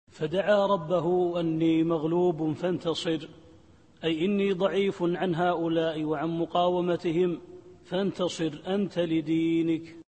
التفسير الصوتي [القمر / 10]